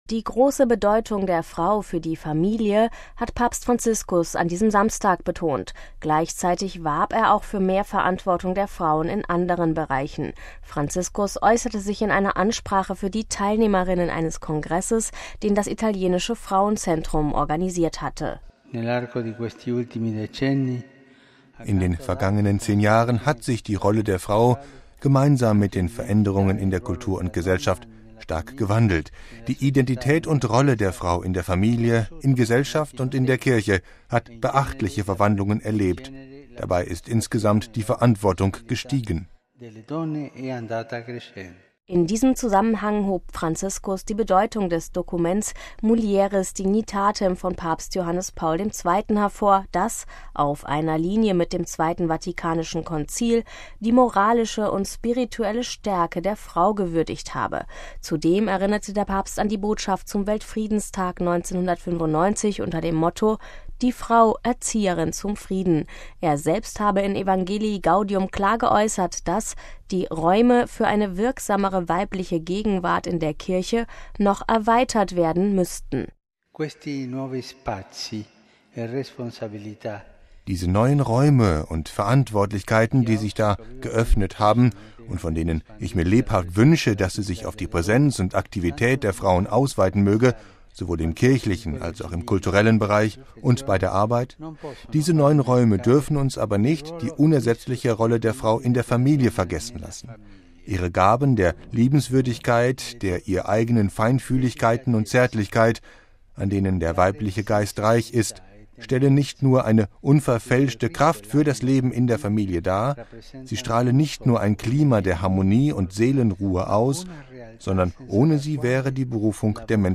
Gleichzeitig warb er auch für mehr Verantwortung der Frauen in anderen Bereichen. Franziskus äußerte sich in einer Ansprache für die Teilnehmerinnen eines Kongresses, den das Italienische Frauenzentrum (CIF) organisiert hatte.